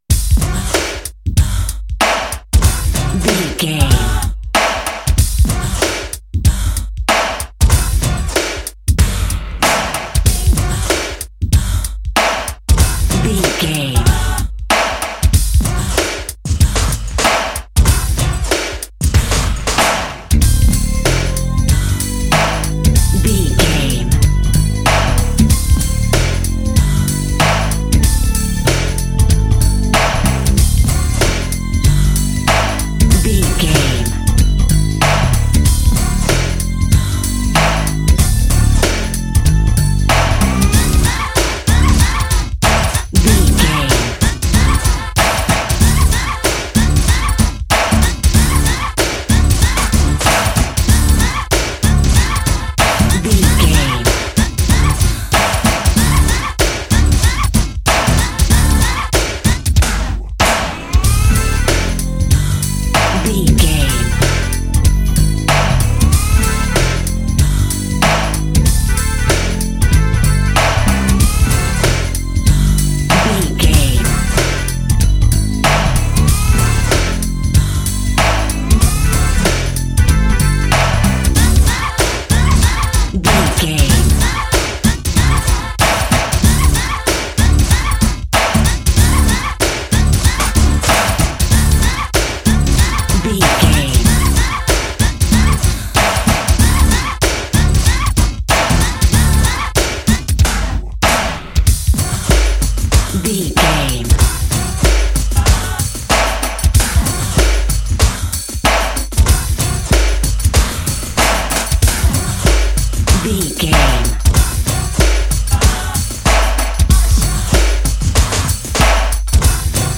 Aeolian/Minor
drum machine
synthesiser
90s
Eurodance